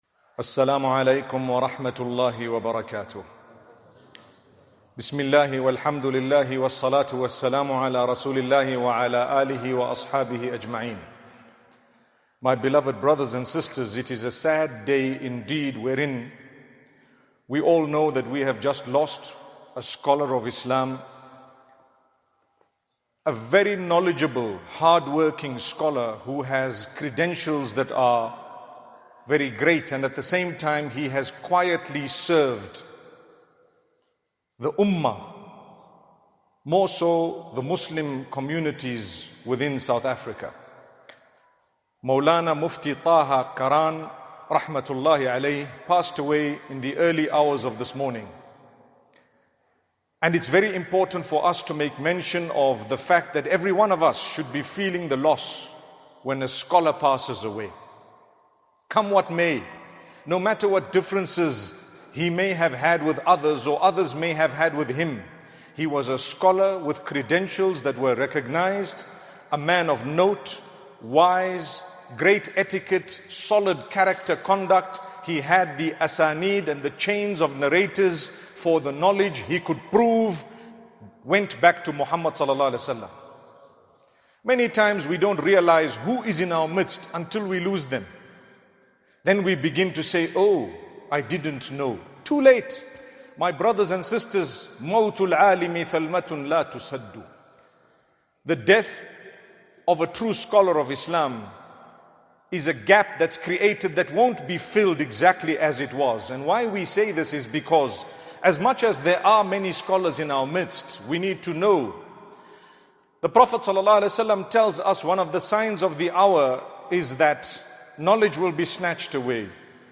A GREAT LOSS - The Death of a Scholar - Friday Sermon by Mufti Menk.mp3